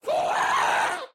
mob / ghast / scream4.ogg
scream4.ogg